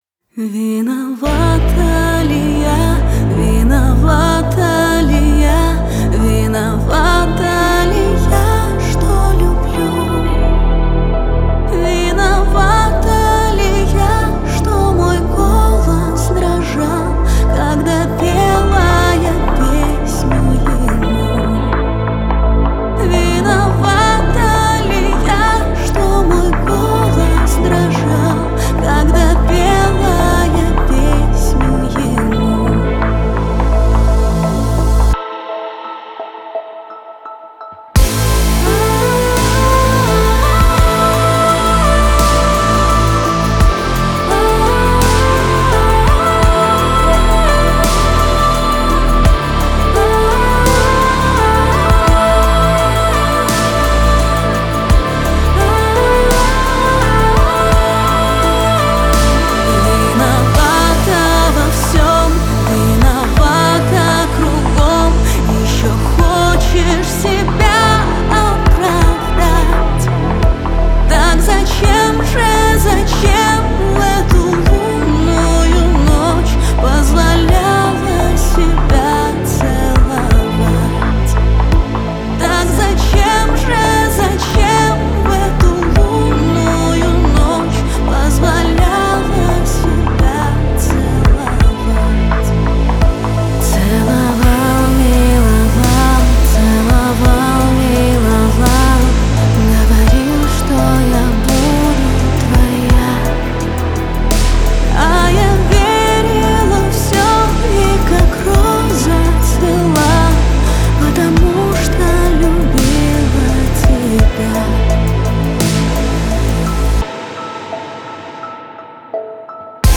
Народная песня;